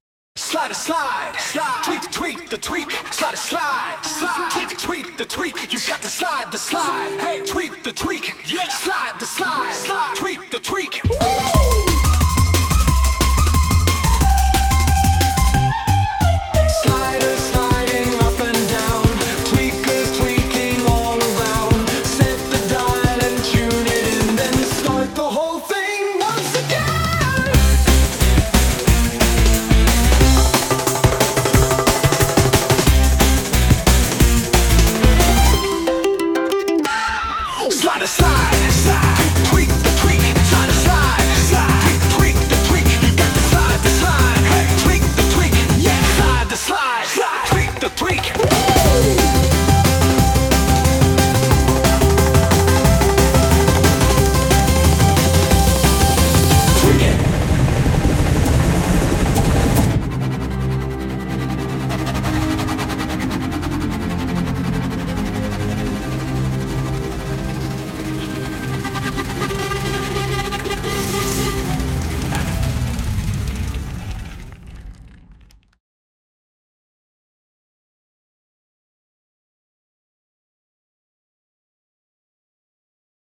Sound Imported : Turbulent Environment
Sung by Suno